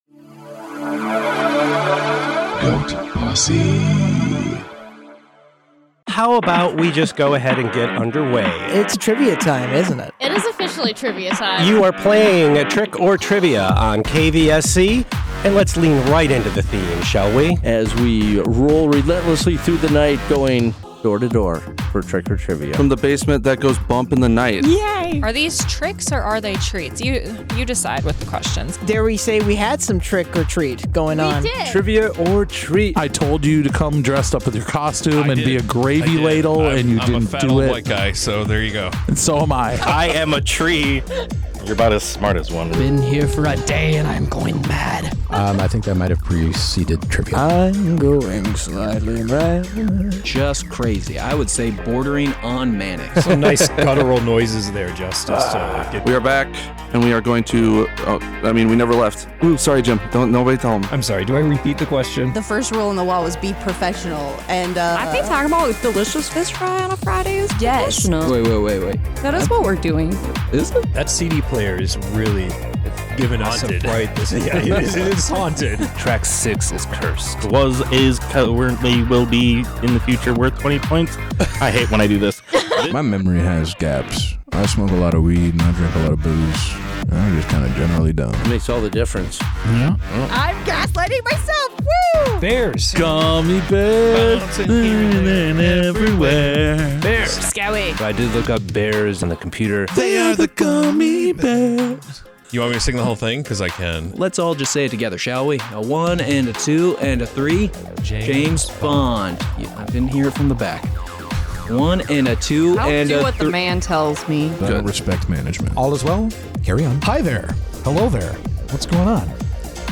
The Year In Review (YIR) started in the Middle-Aughts for no other reason that a lot of leftover thoughts on tape that were dying for a music bed. These days the YIR is handled by more talented Goat Posse members that can actually hit a deadline. 2025’s version suggests quite a chaotic weekend in the basement that goes bump in the night, including: Singing about Bears, a haunted CD player, and a shart confession.